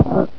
pain1.ogg